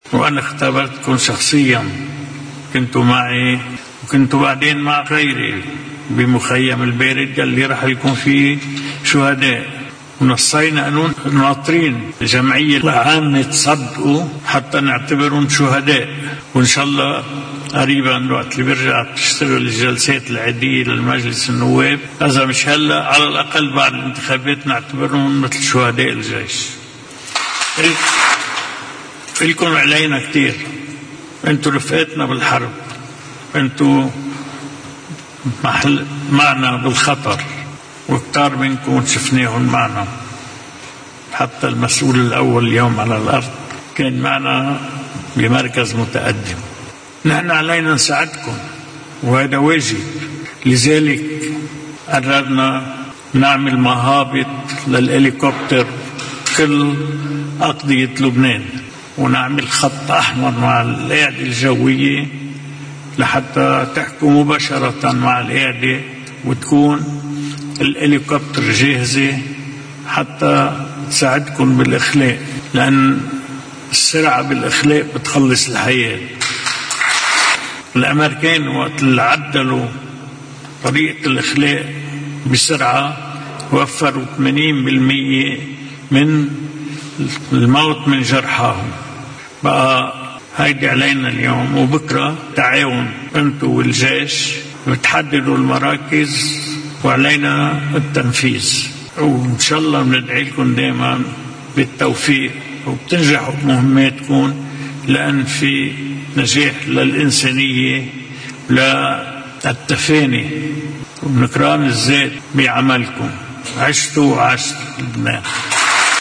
مقتطف من حديث الرئيس ميشال عون في حفل اطلاق الحملة المالية للصليب الاحمر اللبناني 2017 في قصر بعبدا: